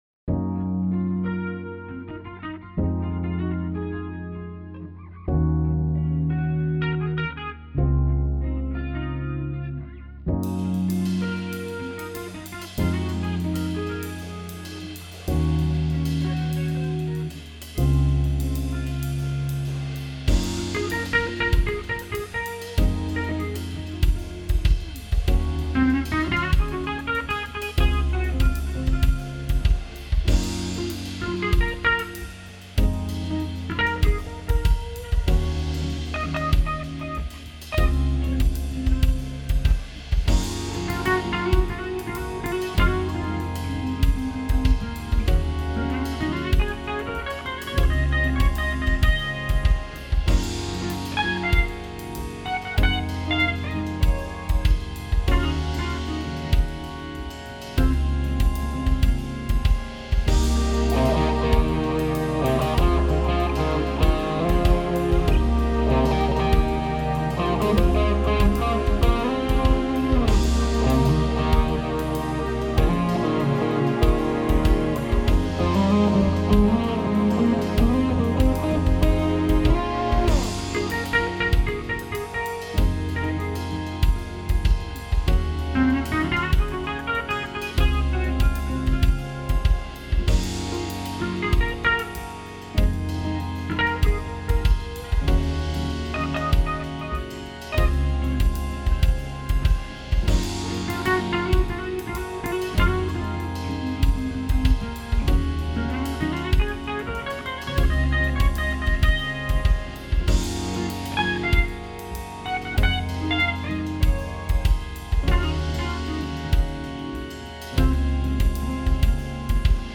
Instrumental
Relaxing guitar music / Musik zum Chillen